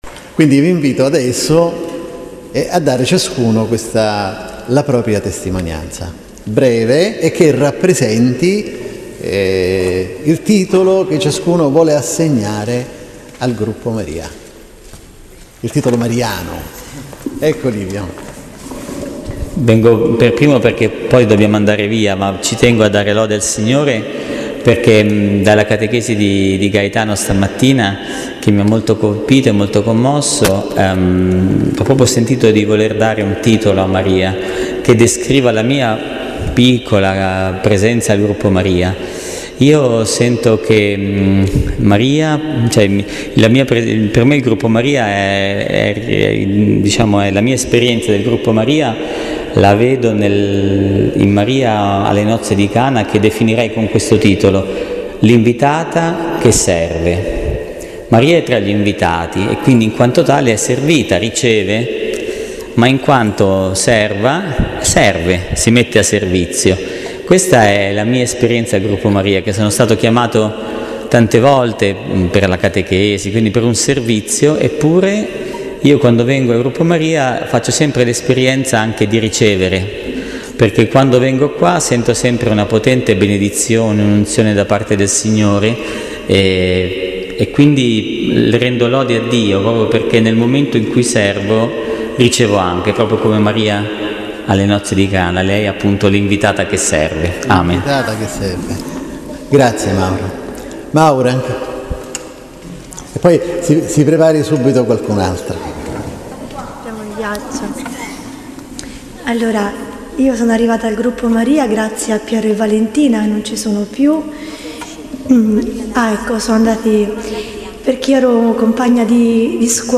Domenica 8 Dicembre 2019 Santa Maria della Consolazione Ritiro dell'Immacolata
Condivisione e Testimonianze sul tema della giornata